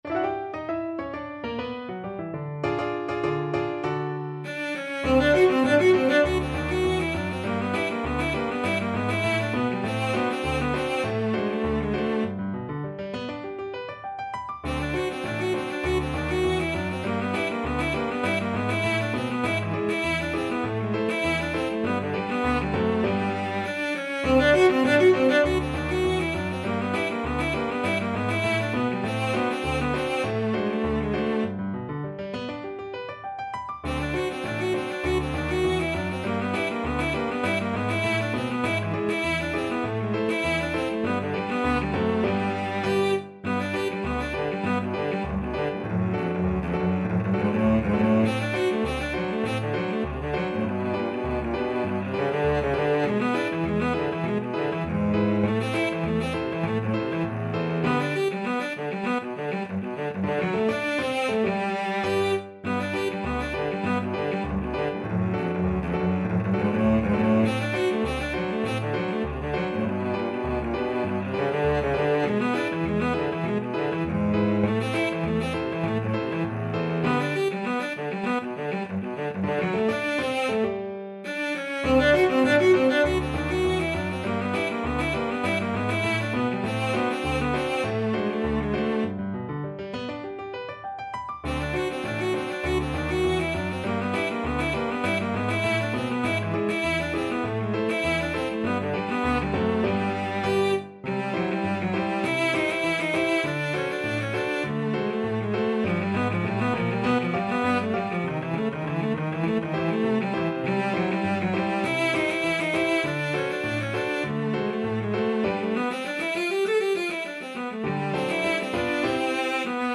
Cello
Moderato
G major (Sounding Pitch) (View more G major Music for Cello )
2/4 (View more 2/4 Music)
Classical (View more Classical Cello Music)